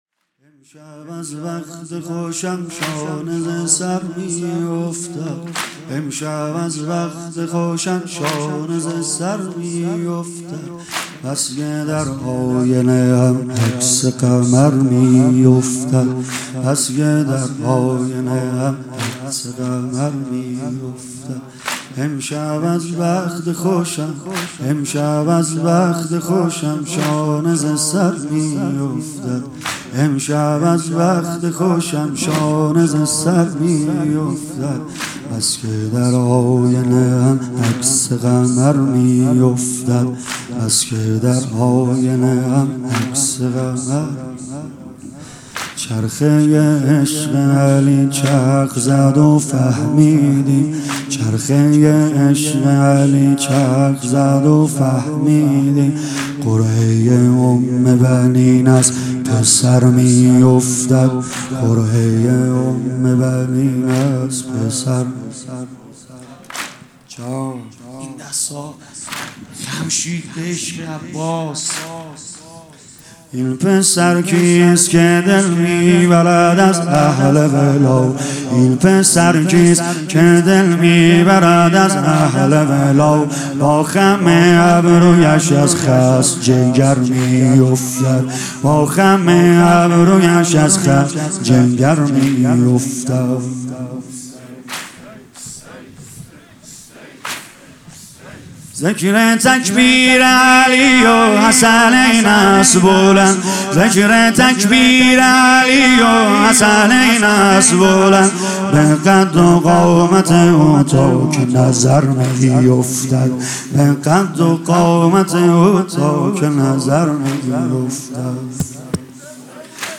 واحد | شیر بی باک علی رفت به میدان
شب هشتم محرم ۹۹ - هیئت فدائیان حسین